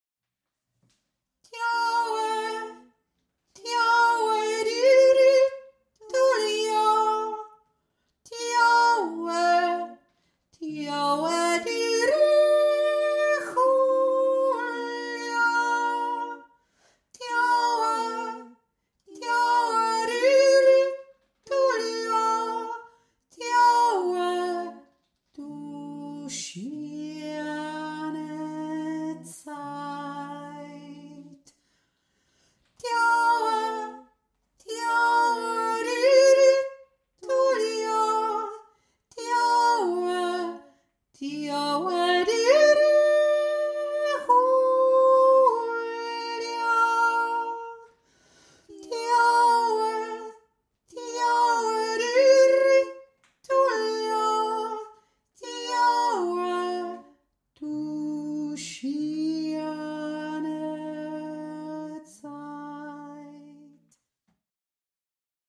1. Stimme
aiblinger-weise-hohe-stimme.mp3